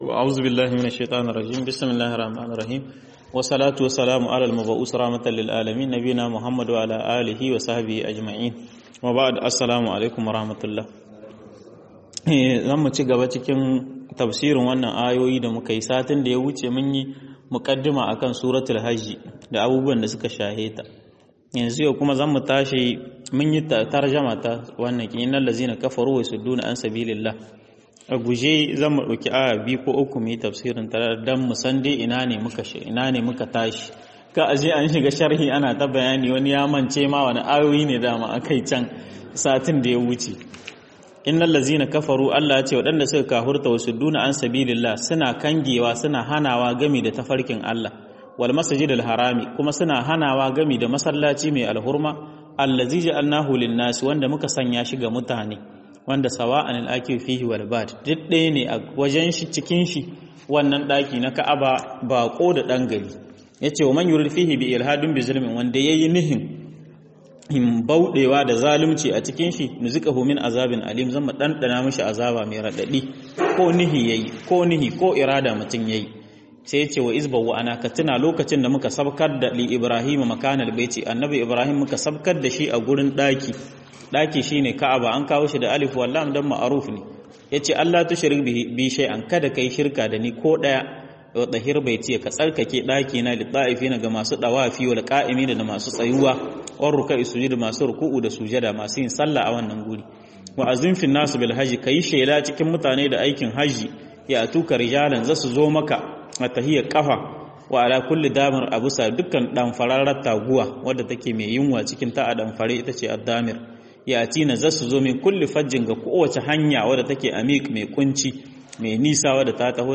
هدايات آيات الحج ٢ - MUHADARA